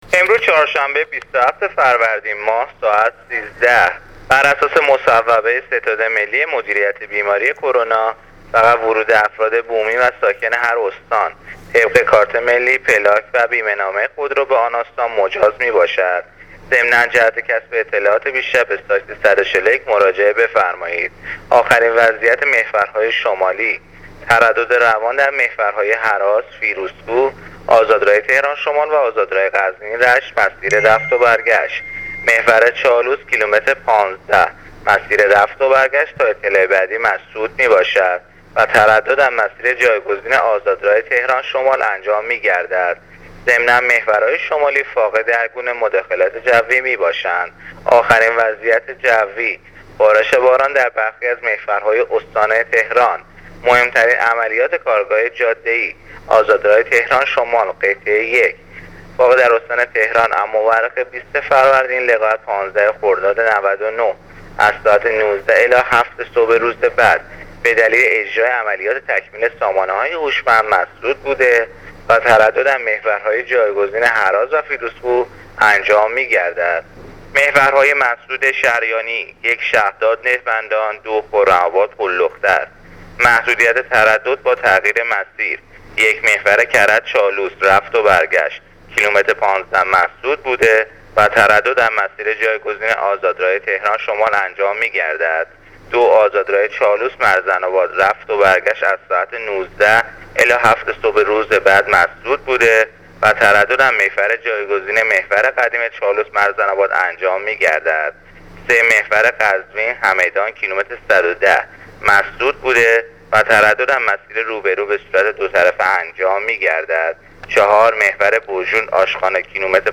گزارش رادیو اینترنتی از آخرین وضعیت ترافیکی جاده‌ها تا ساعت ۱۳ بیست و هفتم فروردین ۱۳۹۹